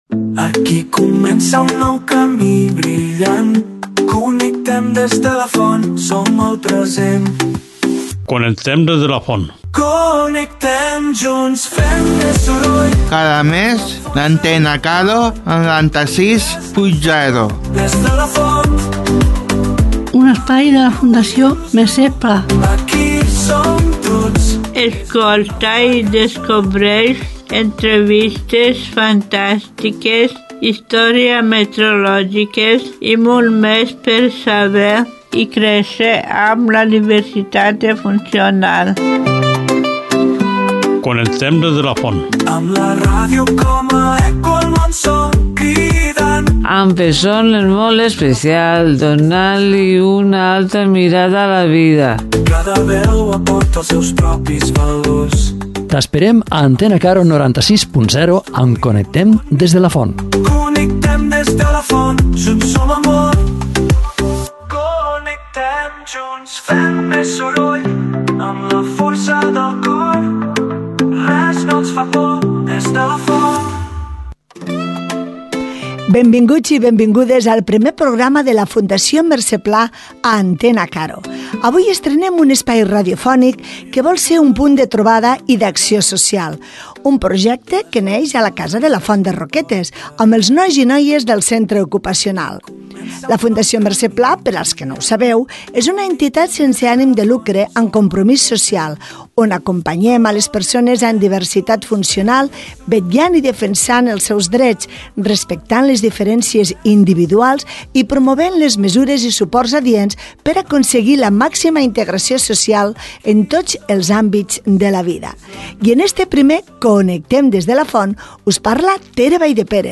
En aquest primer programa, entrevistem a Cinta Garcia, regidora de Servei a les Persones, Igualtat i Joventut, de l’Ajuntament de Roquetes.